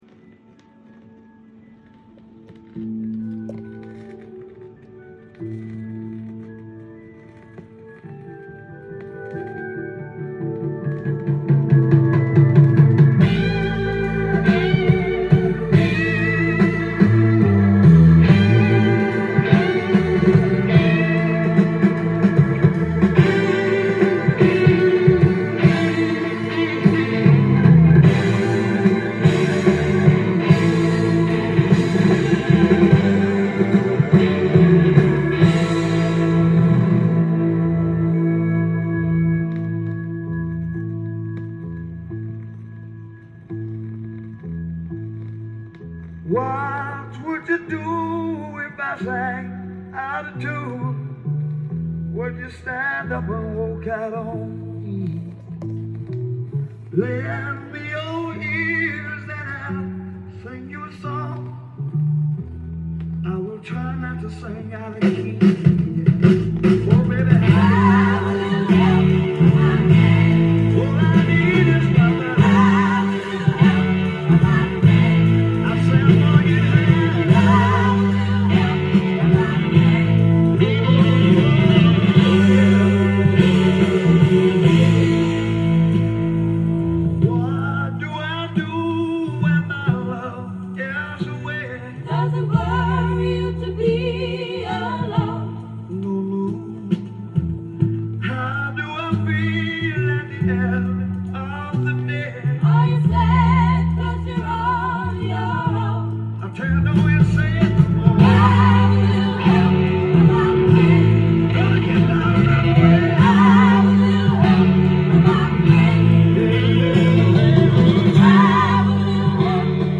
店頭で録音した音源の為、多少の外部音や音質の悪さはございますが、サンプルとしてご視聴ください。
UKのロック/ブルースシンガー